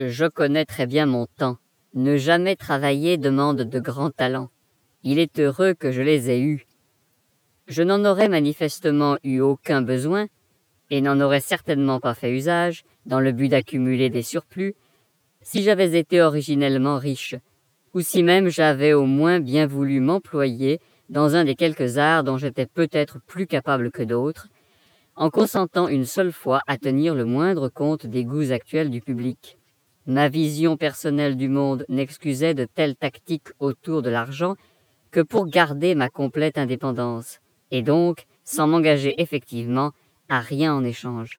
1   Transposition de la voix parlée
Une voix
Transposition -1.5 tons sans correction avec correction de l'enveloppe spectrale